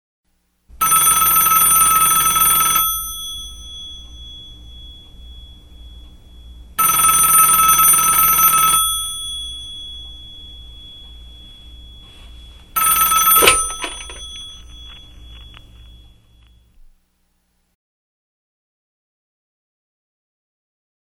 Если Вы имеете ввиду звонки старых тлф, то держите.